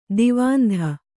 ♪ divāndha